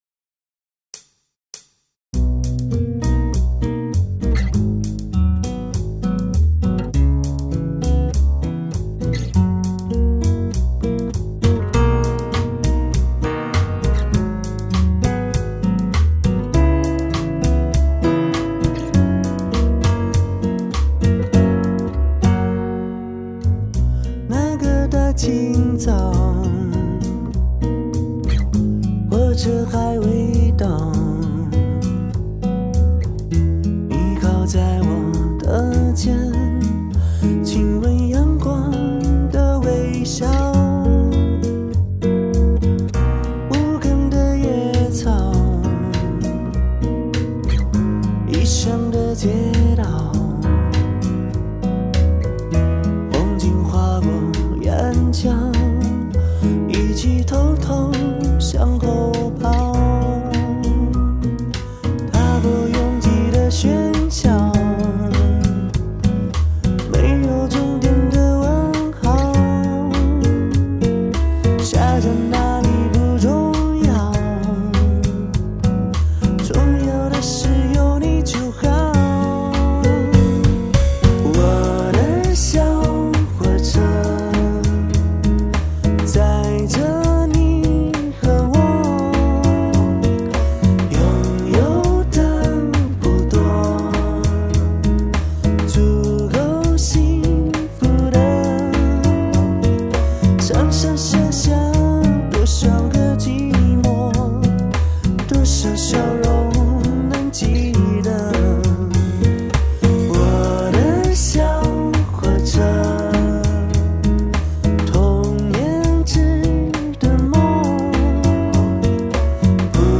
柔软却不失力道